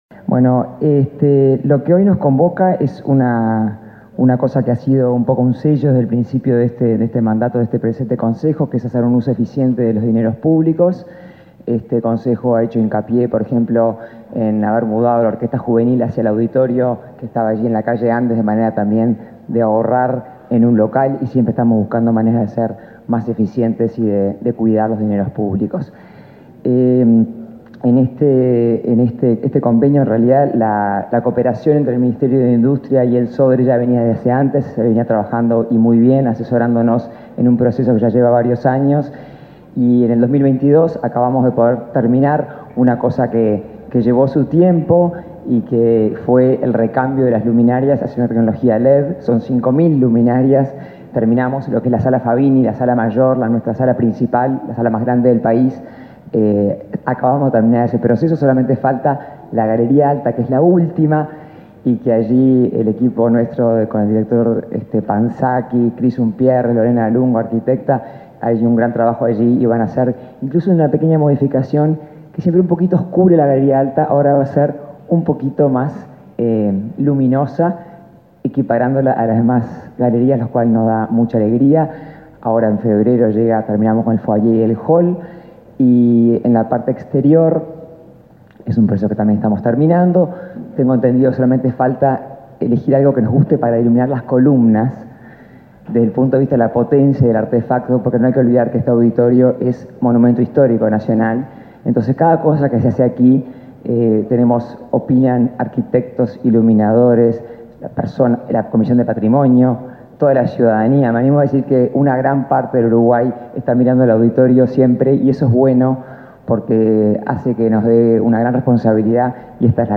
Palabras de autoridades en convenio entre Sodre y Dirección Nacional de Energía
Palabras de autoridades en convenio entre Sodre y Dirección Nacional de Energía 31/01/2023 Compartir Facebook X Copiar enlace WhatsApp LinkedIn El Sodre y la Dirección Nacional de Energía suscribieron un acuerdo a través del Fideicomiso Uruguayo de Ahorro y Eficiencia Energética, con el objetivo de estimular el uso eficiente de la energía y la movilidad eléctrica. La presidenta del Sodre, Adela Dubra; el director de Energía, Fitzgerald Cantero; el ministro de Industria, Omar Paganini, y su par de Educación y Cultura, Pablo da Silveira, señalaron la importancia del convenio.